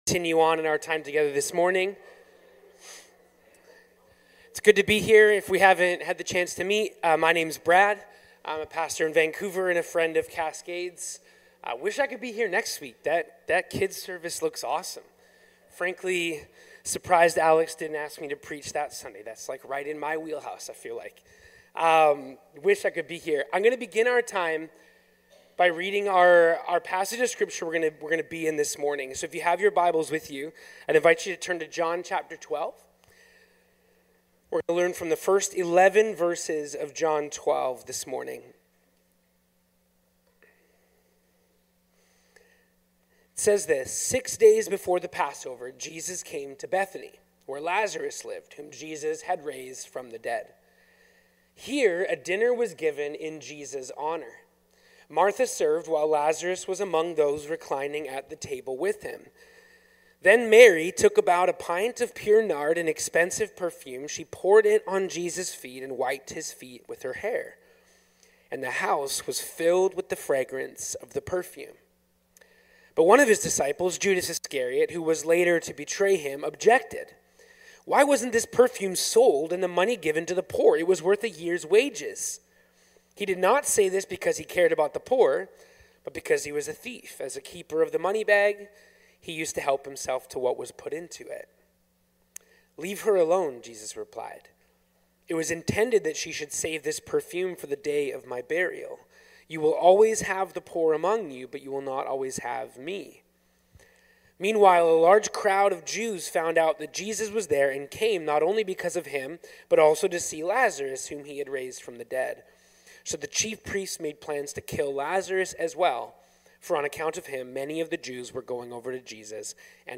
Cascades Church Sermons Untamed Devotion | John 12:1-11 Play Episode Pause Episode Mute/Unmute Episode Rewind 10 Seconds 1x Fast Forward 30 seconds 00:00 / 00:43:10 Subscribe Share Apple Podcasts RSS Feed Share Link Embed